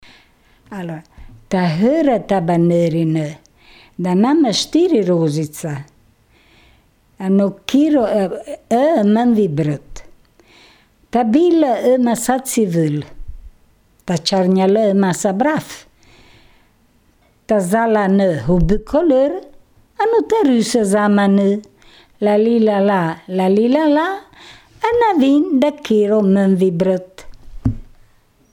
Si tratta di due filastrocche, una preghiera e di parole di una canzone.